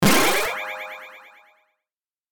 Download Free Level Up Sound Effects | Gfx Sounds
Builder-game-power-up-item.mp3